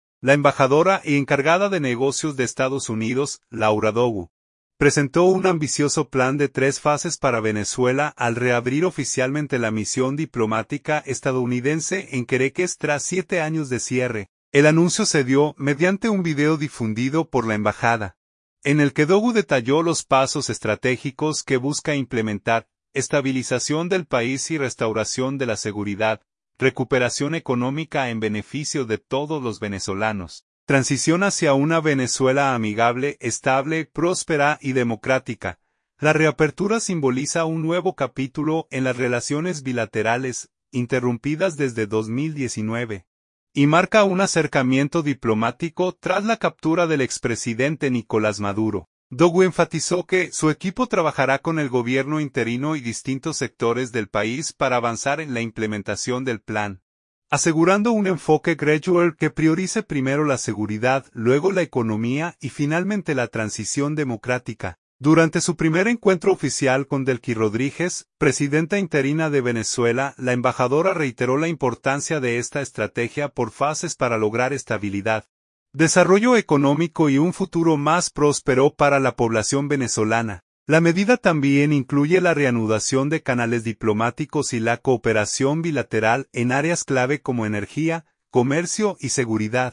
El anuncio se dio mediante un video difundido por la Embajada, en el que Dogu detalló los pasos estratégicos que busca implementar: